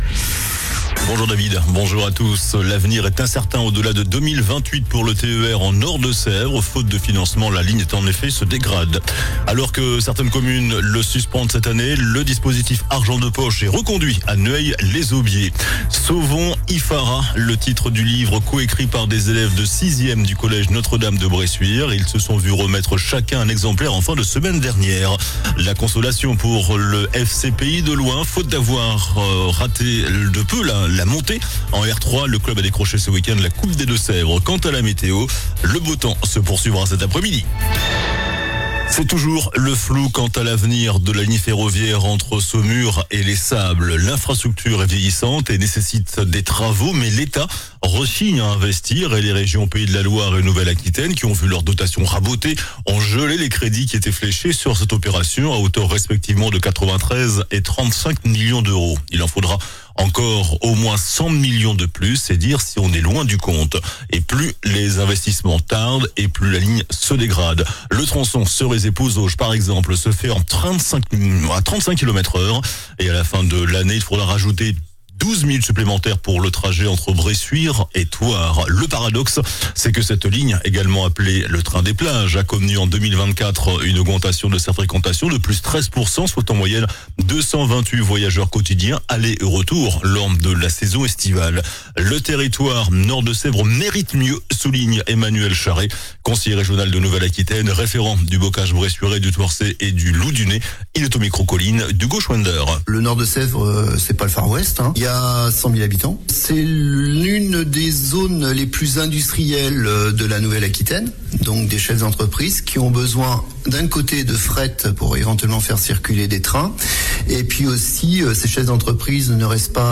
JOURNAL DU LUNDI 16 JUIN ( MIDI )